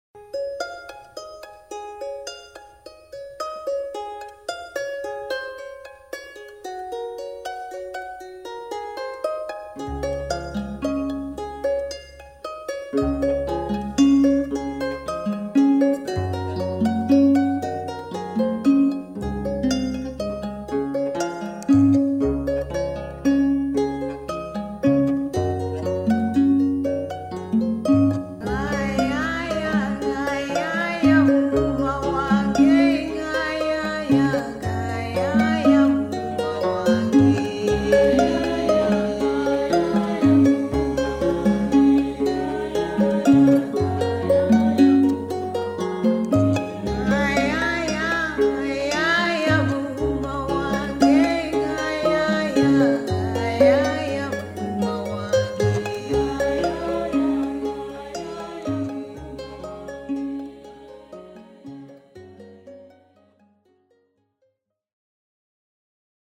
• Melodies and rhythms from across Africa
Hailing from various parts of Surrey and Greater London, this energetic drumming and dancing troupe stage fascinating displays of traditional African rhythms, songs and dances.